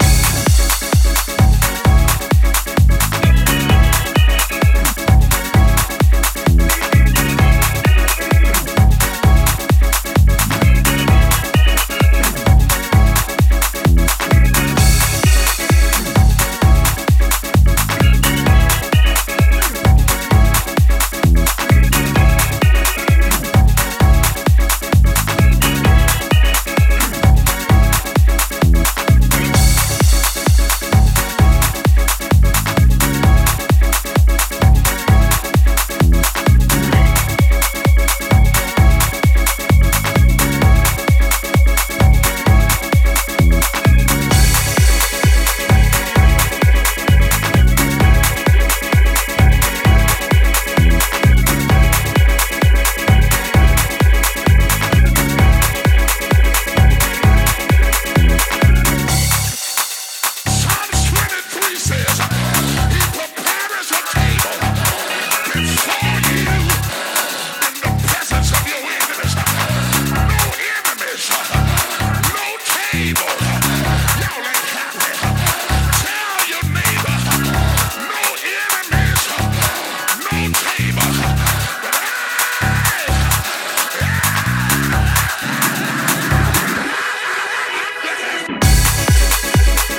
ジャンル(スタイル) DEEP HOUSE / HOUSE / DETROIT